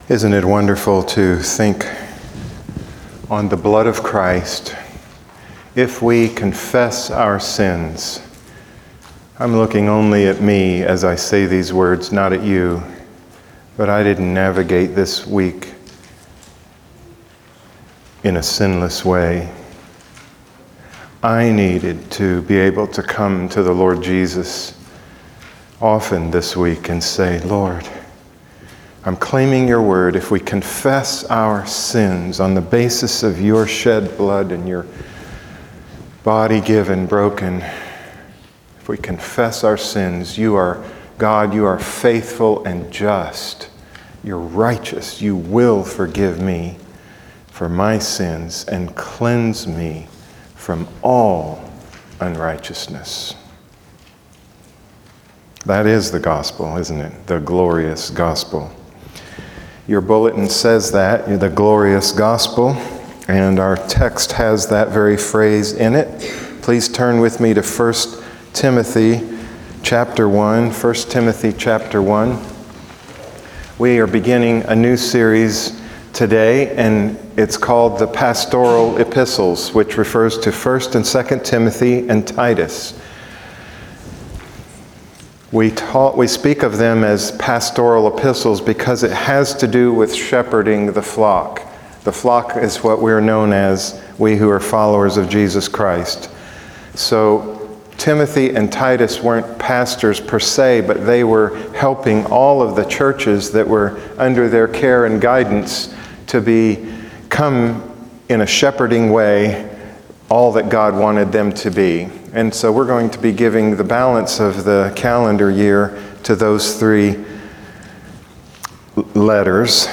Sunday messages from a community of Christ-followers caring for and serving each other, worshiping God, and extending the grace of Jesus Christ to our community.